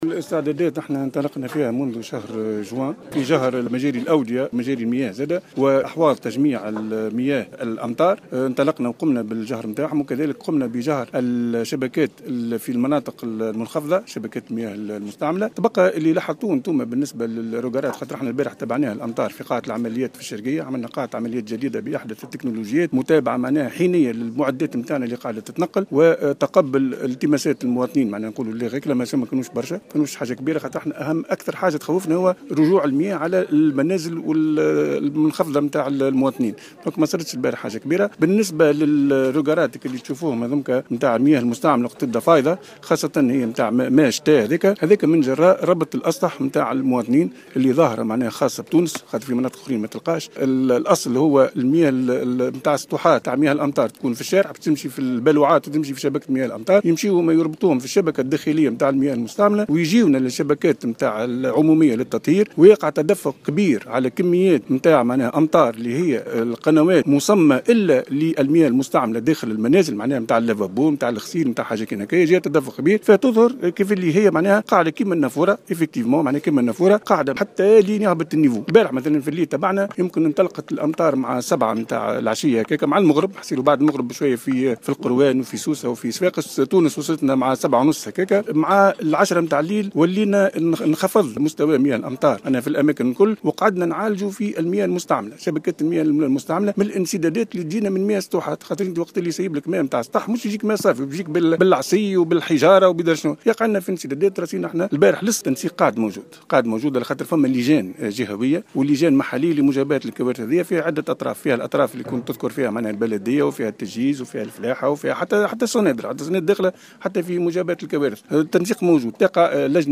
أكد الحبيب عمران مدير عام الديوان الوطني للتطهير في تصريح لمراسل الجوهرة أف أم...